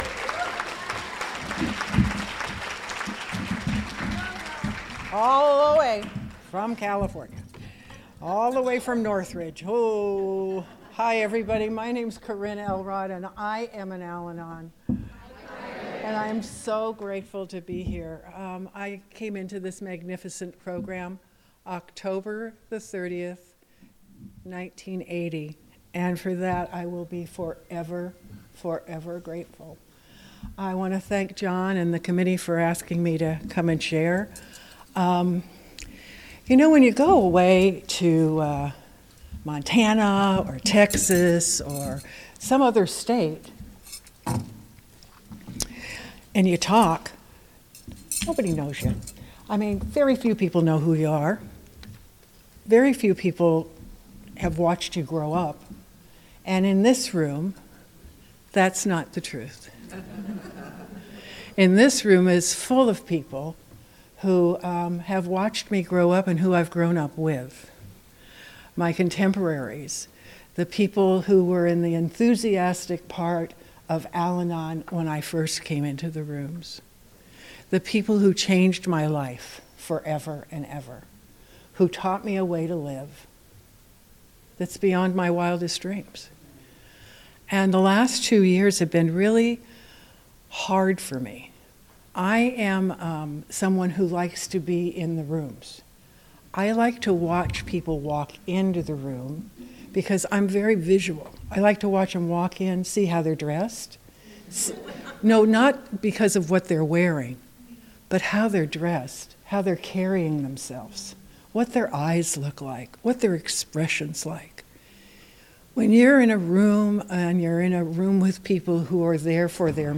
45th Southern California Al-Anon Family Groups Convention